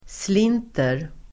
Ladda ner uttalet
Uttal: [sl'in:ter]